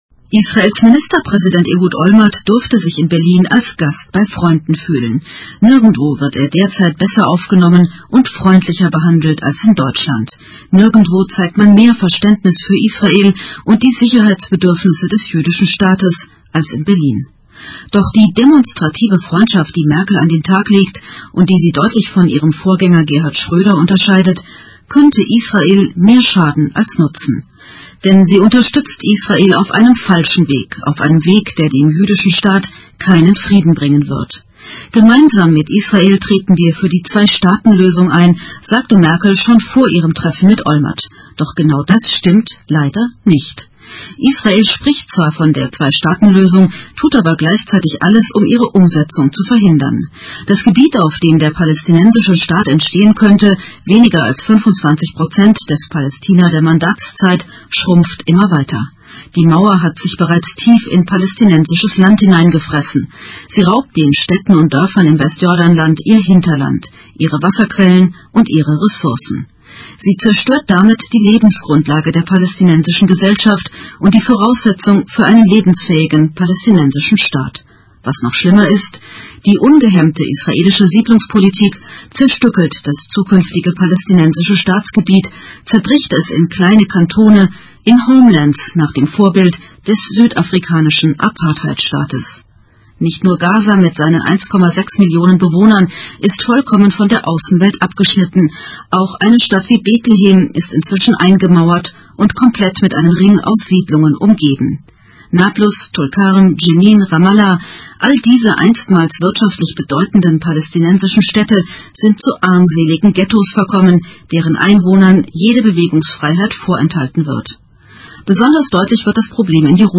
Audio-Kommentar zum Treffen mit Olmert
Kommentar im Deutschlandfunk am 12.02.